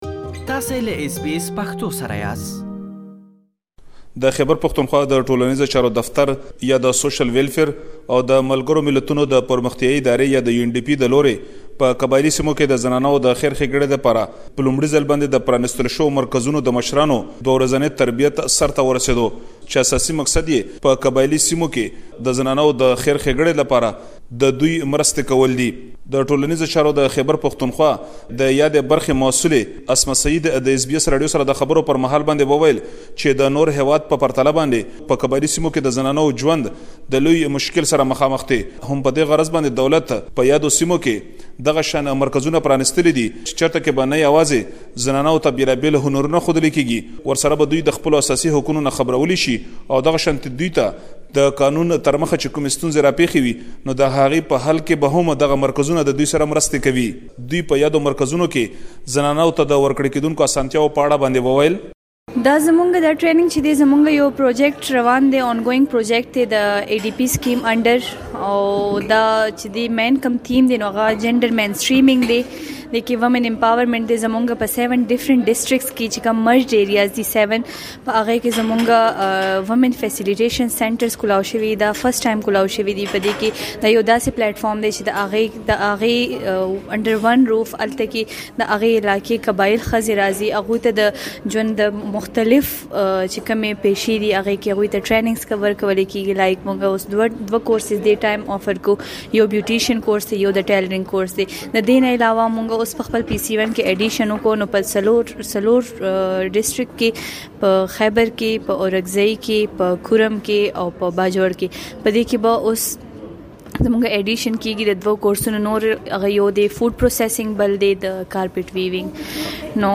نور په رپوت کې واورئ.